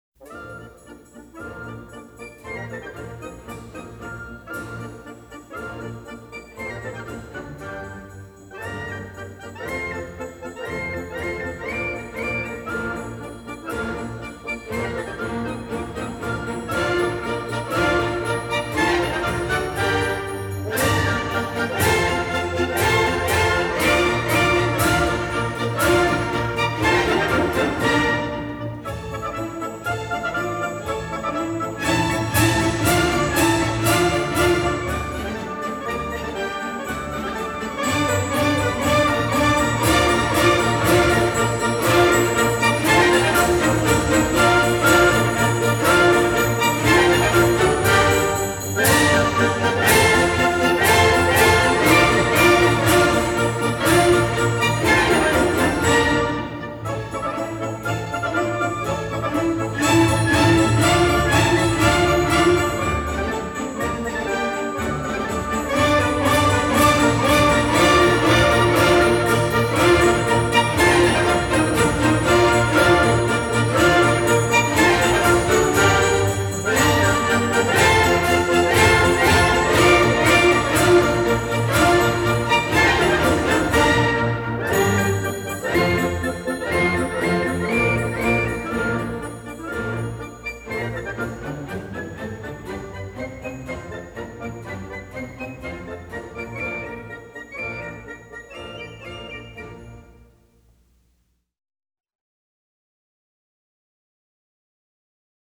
1809   Genre: Classical   Artist